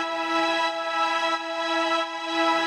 SaS_MovingPad05_90-E.wav